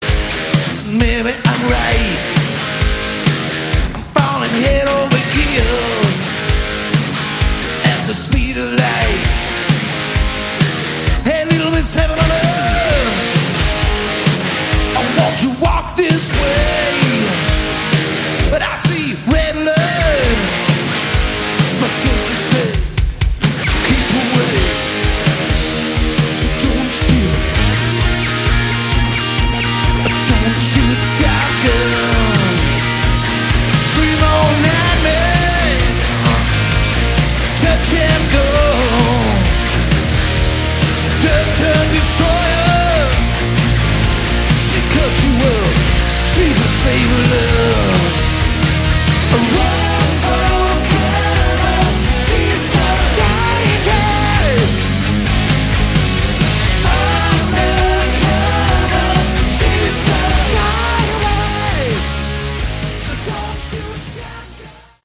Category: Hard Rock
Recorded on their Las Vegas residency at 'The Joint' in 2013